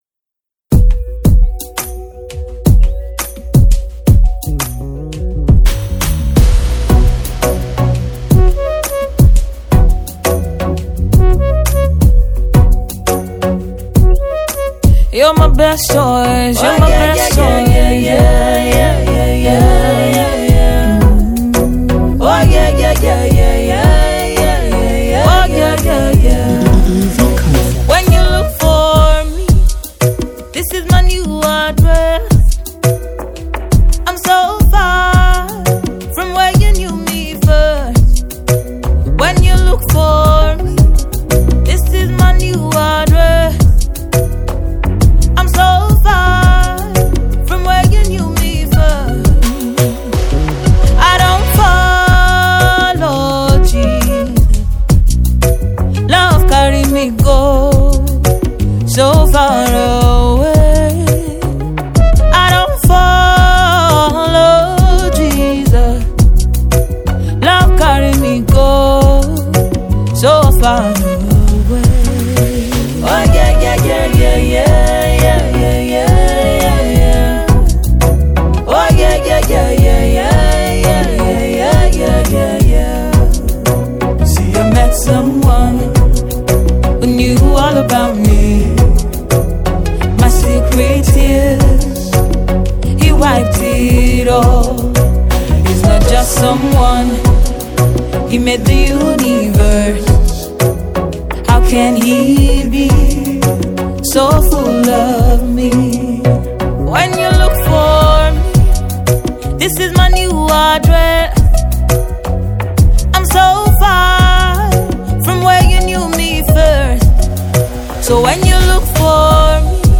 Mp3 Gospel Songs
soulful melodies and introspective lyrics